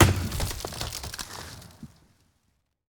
car-tree-wood-impact-04.ogg